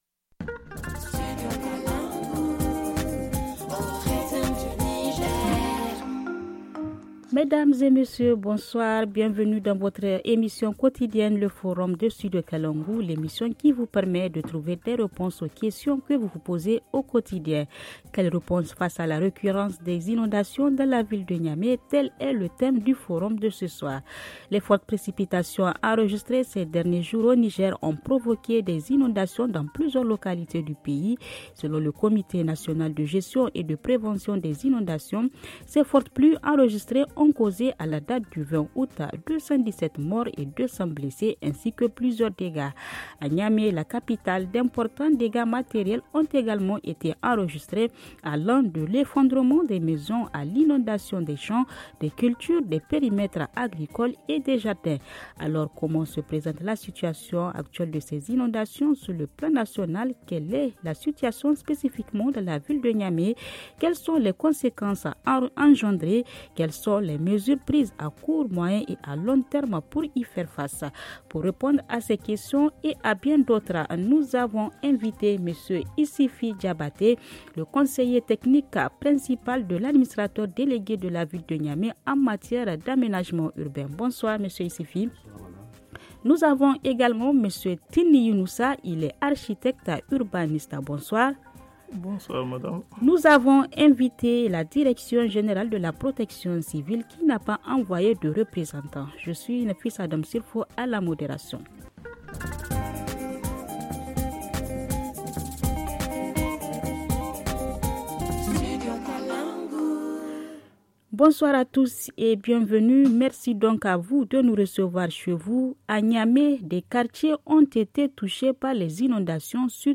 FR Le forum en français Télécharger le forum ici.